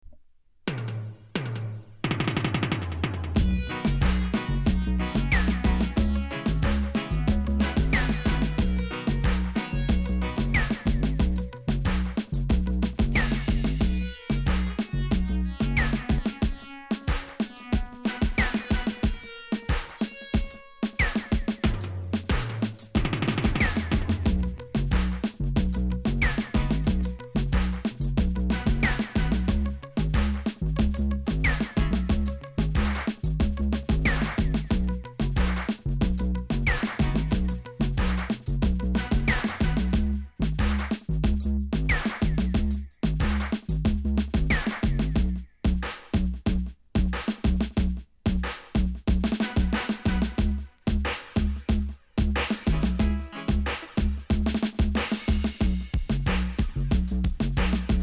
※多少小さなノイズはありますが概ね良好です。